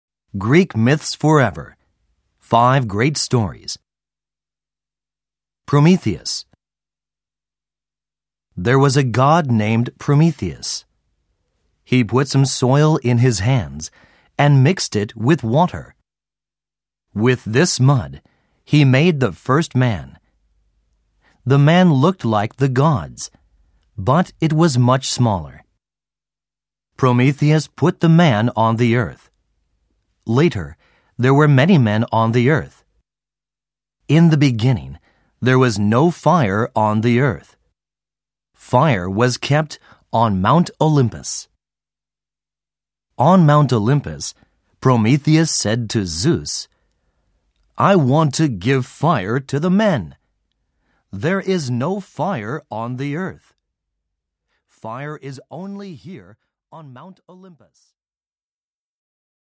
[オーディオブック] きくeigo〜ギリシャ神話
本商品は、英語の朗読による音声ファイルのみの商品となっておりますが、